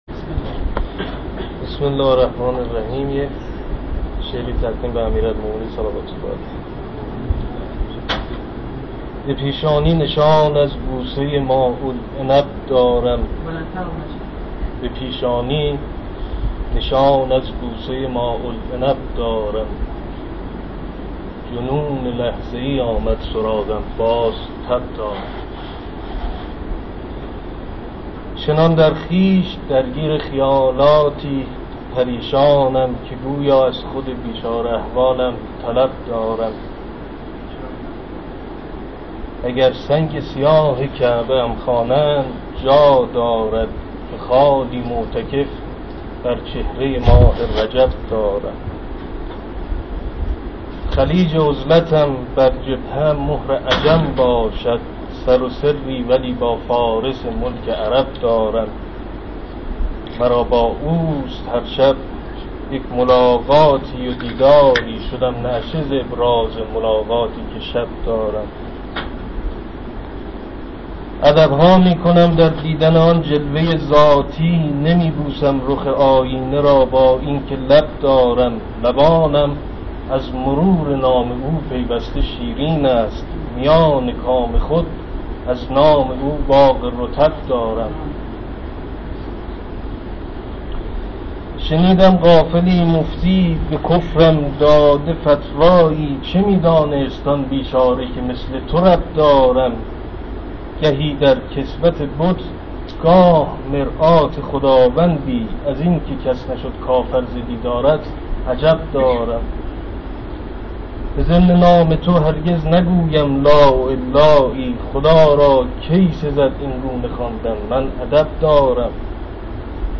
در ادامه متن اشعار و صوت شعر خوانی شعرا را مشاهده می کنید: